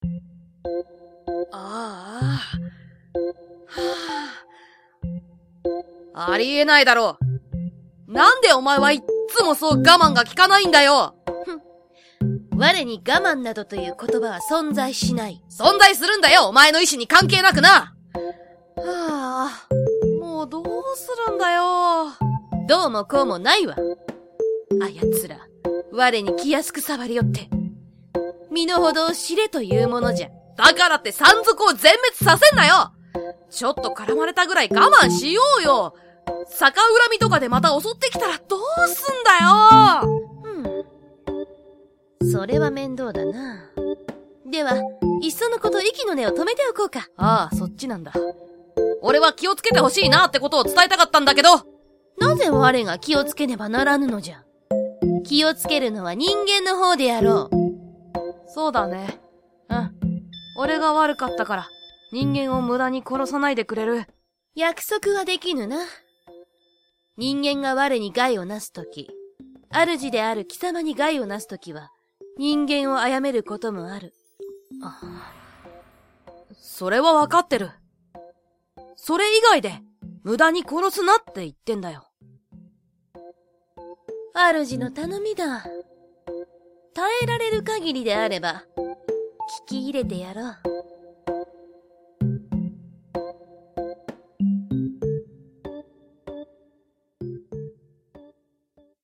voice sample MENU